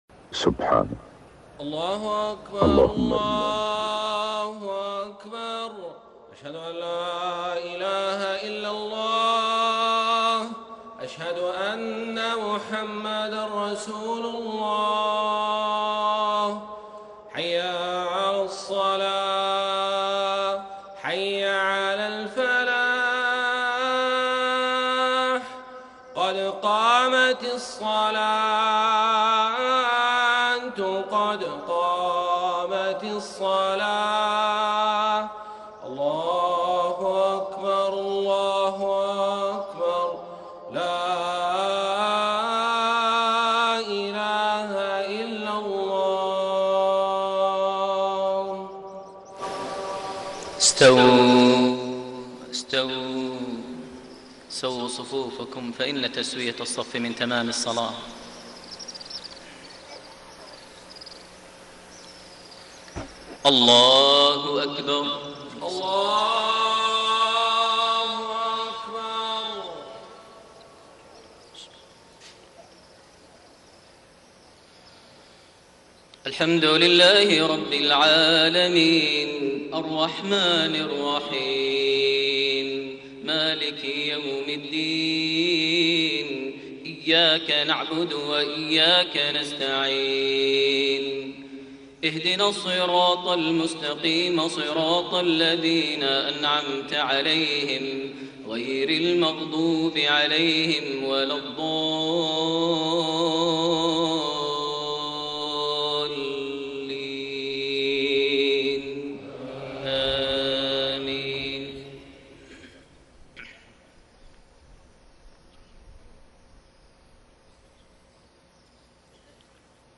صلاة المغرب 8-6-1432 | خواتيم سورة النازعات 15-46 > 1432 هـ > الفروض - تلاوات ماهر المعيقلي